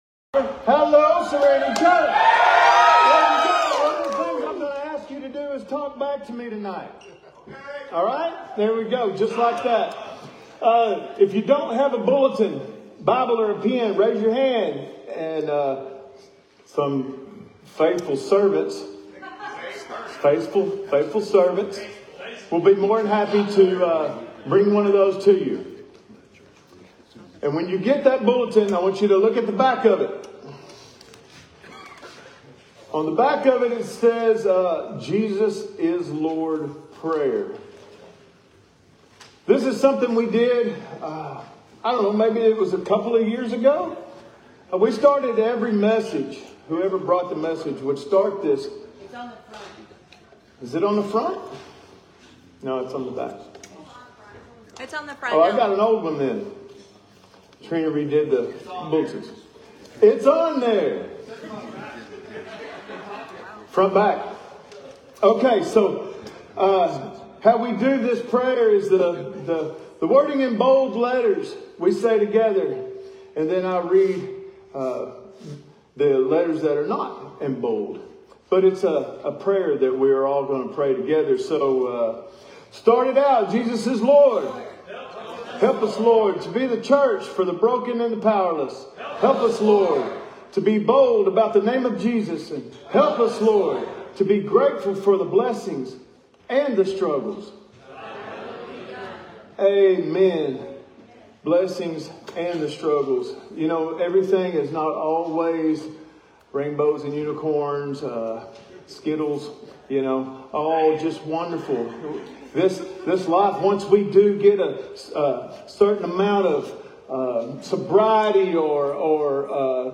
Sermons | Serenity Church